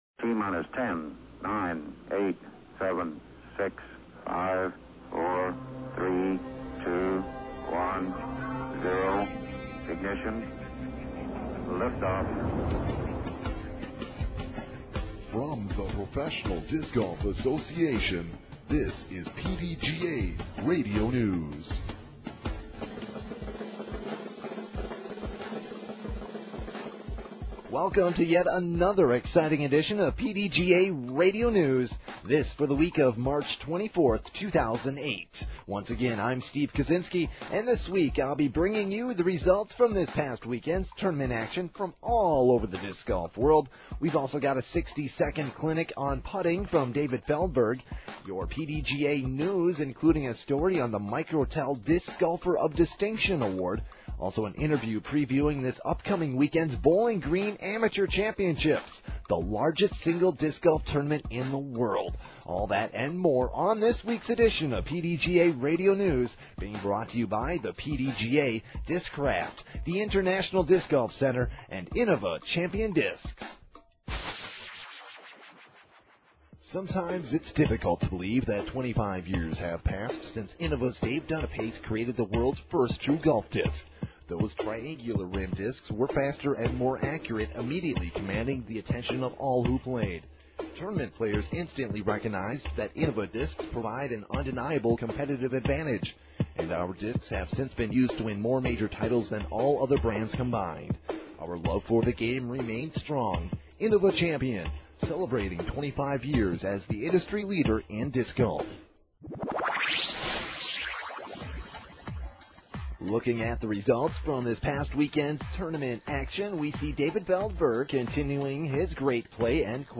and also an interview previewing this upcoming weekend's Bowling Green Amateur Championships, the largest single disc golf tournament in the world.